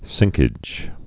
(sĭngkĭj)